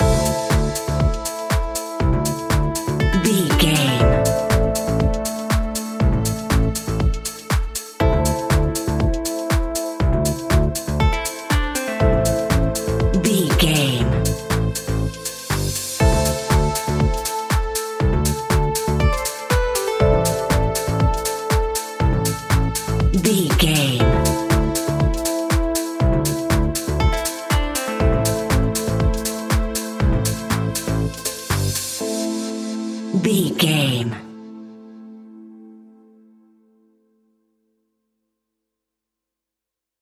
Aeolian/Minor
groovy
uplifting
energetic
happy
bass guitar
strings
electric piano
synthesiser
drum machine
funky house
deep house
nu disco
upbeat
funky guitar
synth bass